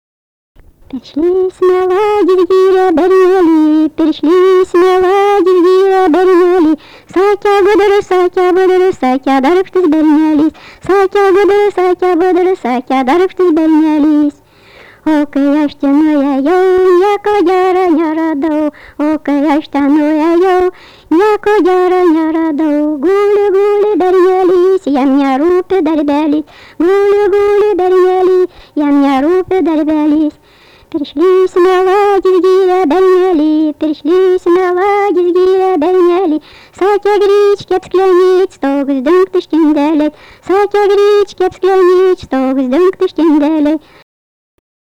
daina, vestuvių
Zastaučiai
vokalinis